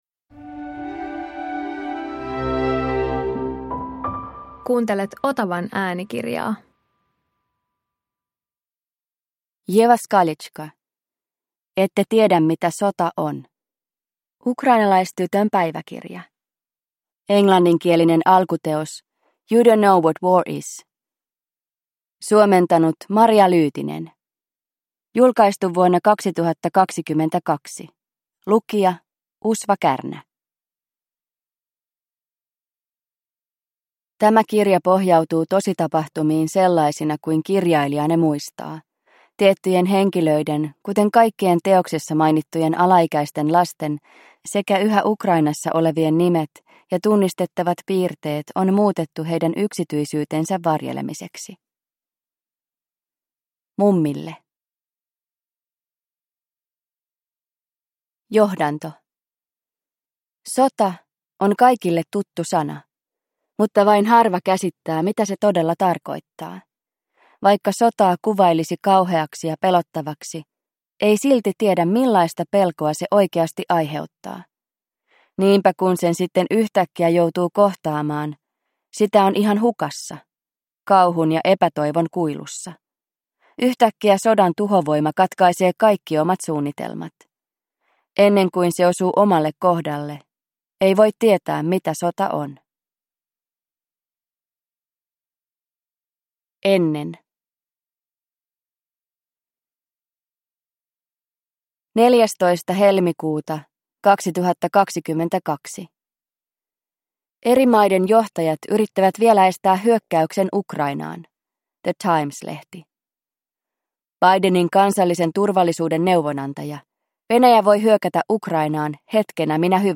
Ette tiedä mitä sota on – Ljudbok – Laddas ner